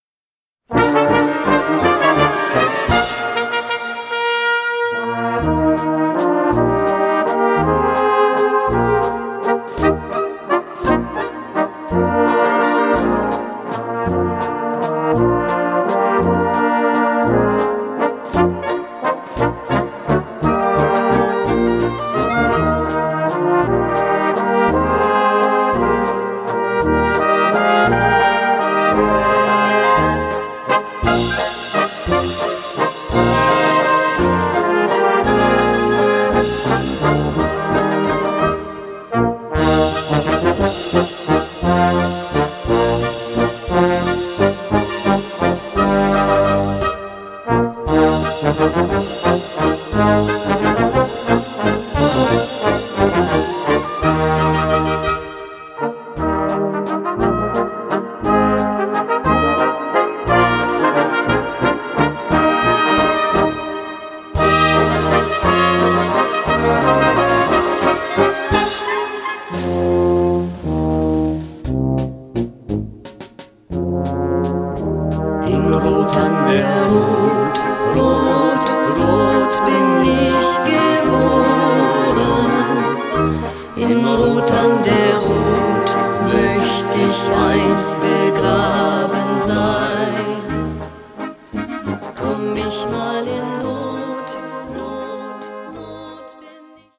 In Rot an der Rot für großes Blasorchester…
Blasmusik